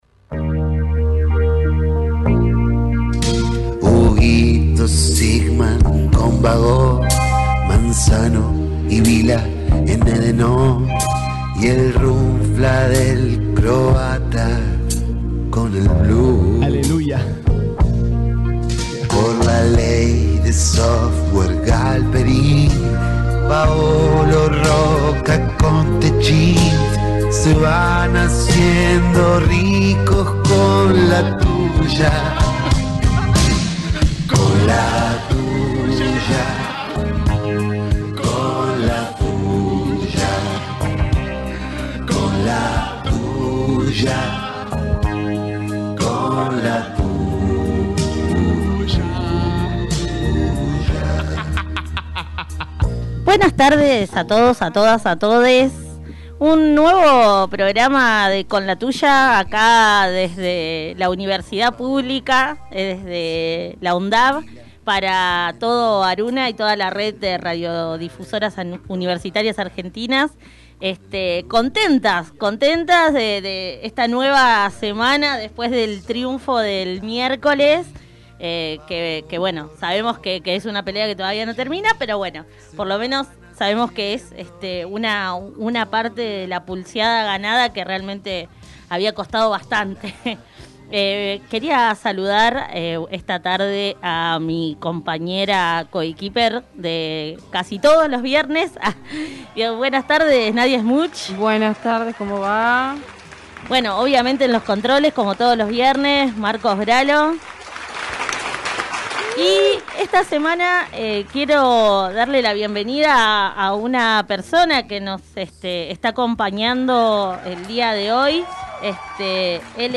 Cuenta también con una columna itinerante, con entrevistas e invitados especiales que serán parte de este programa que sale los viernes de 19 a 20.